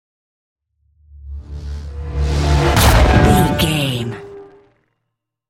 Horror whoosh to hit
Sound Effects
In-crescendo
Atonal
ominous
suspense
haunting
eerie